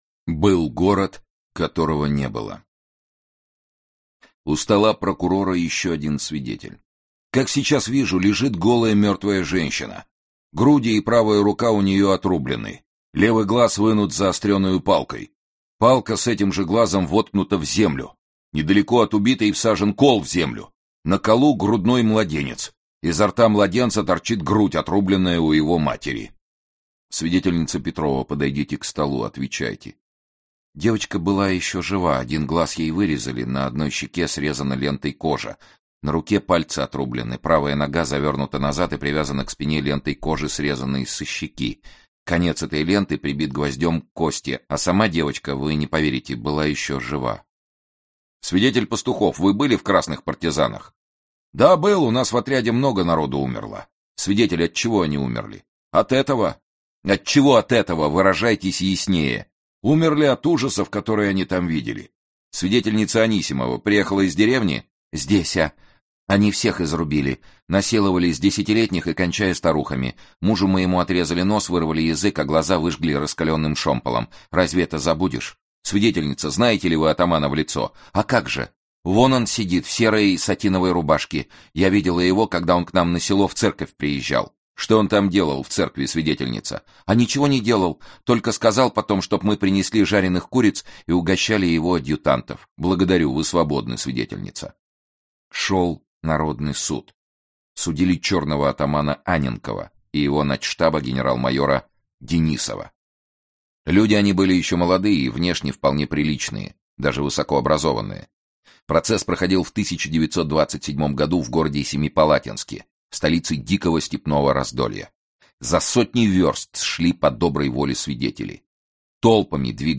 Аудиокнига Портрет из русского музея | Библиотека аудиокниг
Aудиокнига Портрет из русского музея Автор Валентин Пикуль Читает аудиокнигу Сергей Чонишвили.